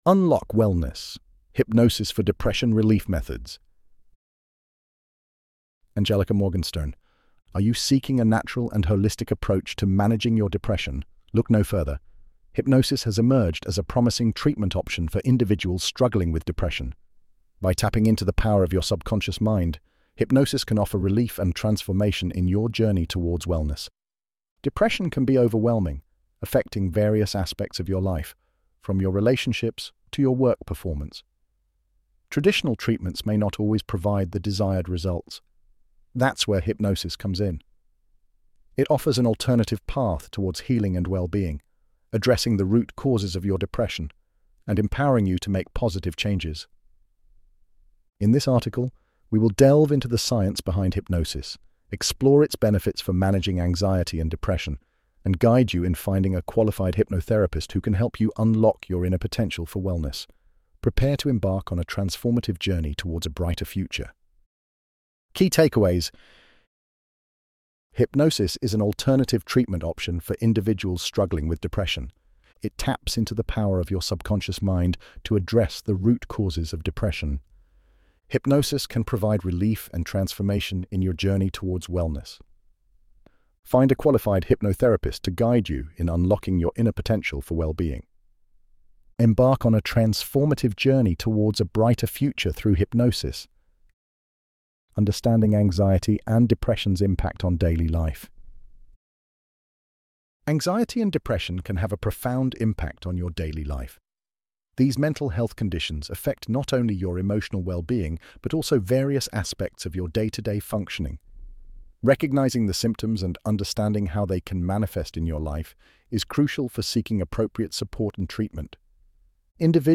ElevenLabs_Unlock_Wellness_Hypnosis_for_Depression_Relief_Methods.mp3